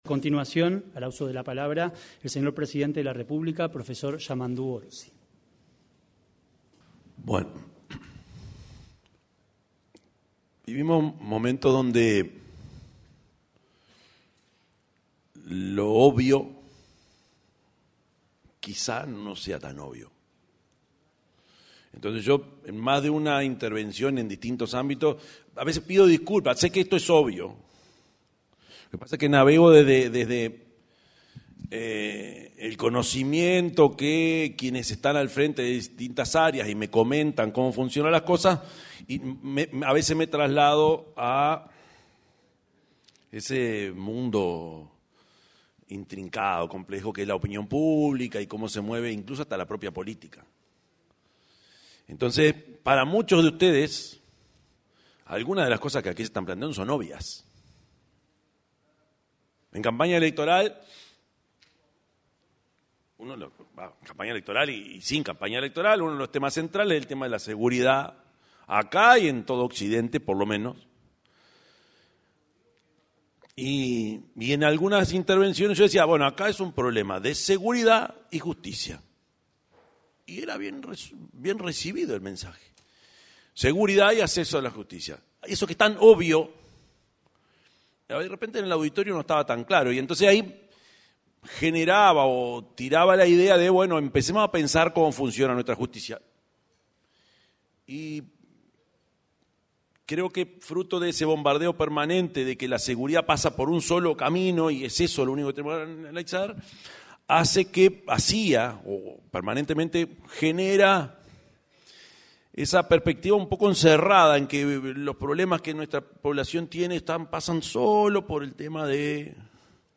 Palabras del presidente de la República, Yamandú Orsi
Palabras del presidente de la República, Yamandú Orsi 25/06/2025 Compartir Facebook X Copiar enlace WhatsApp LinkedIn Se realizó, en el auditorio del edificio anexo a la Torre Ejecutiva, el seminario internacional Hacia un Ministerio de Justicia y Derechos Humanos en Uruguay. En la oportunidad, se expresó el presidente de la República, Yamandú Orsi.